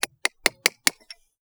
TOOL_Chisel_Sequence_03_mono.wav